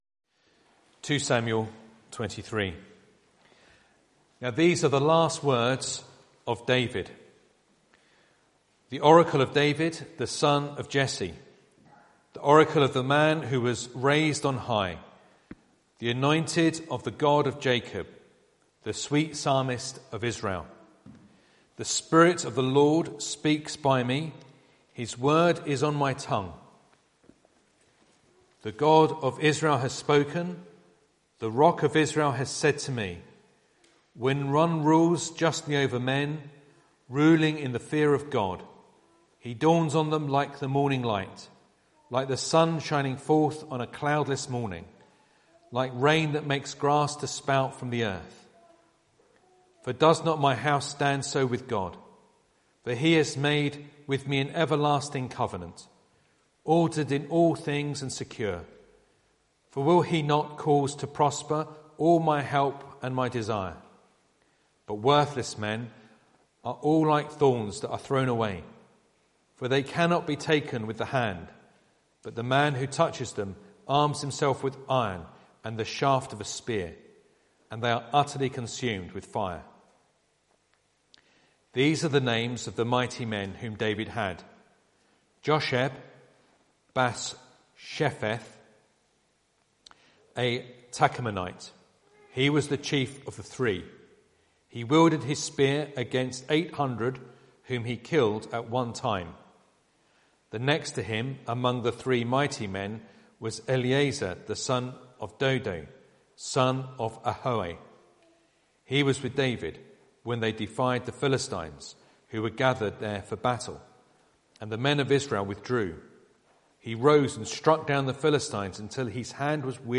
Passage: 2 Samuel 23 Service Type: Sunday Evening